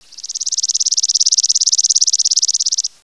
sparrow3.wav